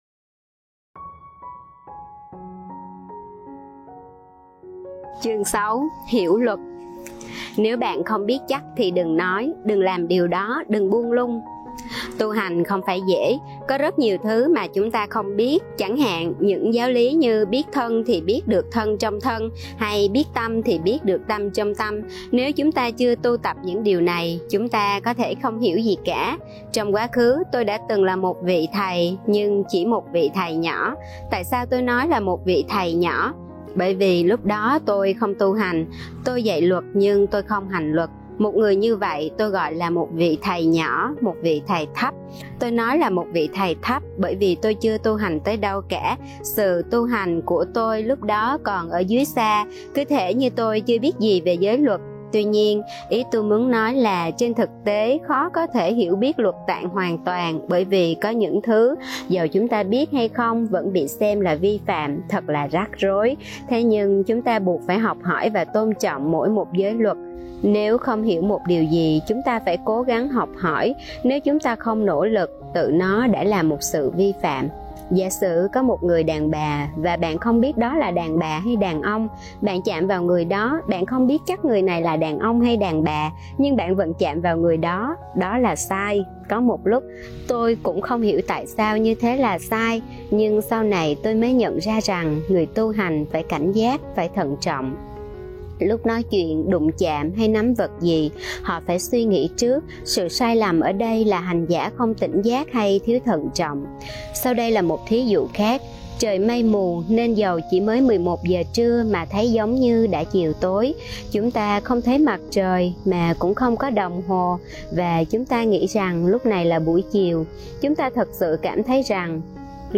Sách nói Suối nguồn tâm linh P2: Điều phục tâm - Ajahn Chah